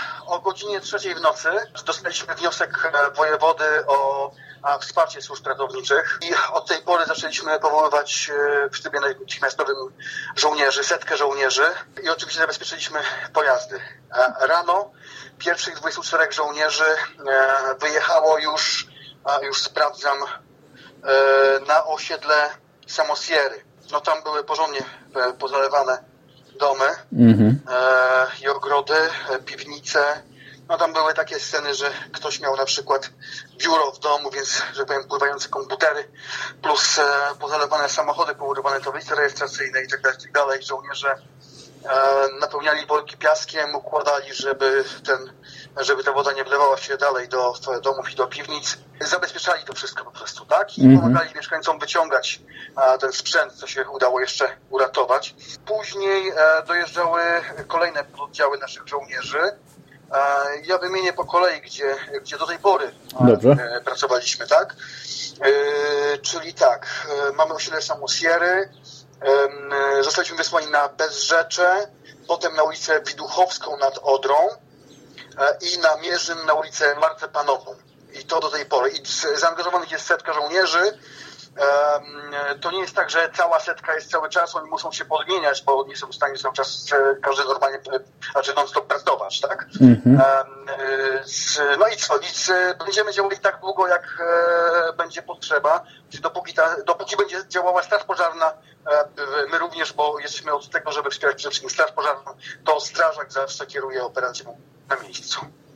W rozmowie z naszą redakcją